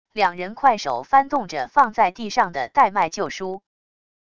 两人快手翻动着放在地上的代卖旧书wav音频